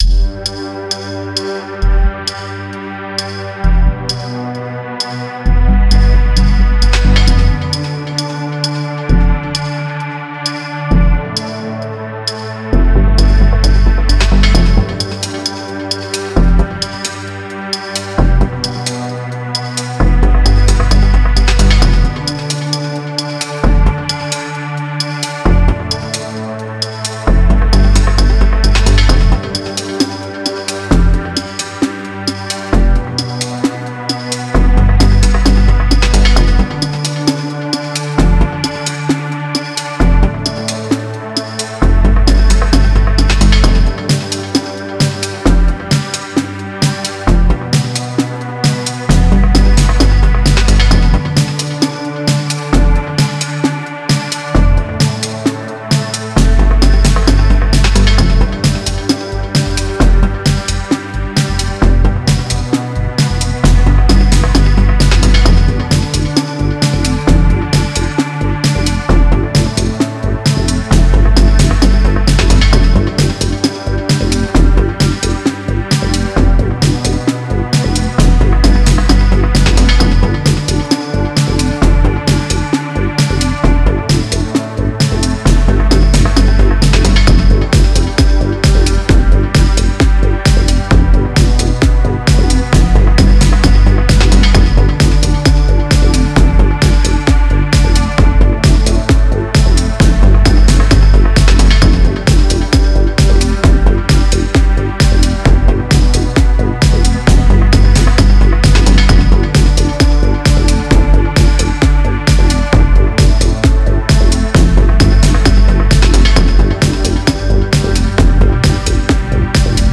Nur Astana Moschee in Nur Sultan Kasachstan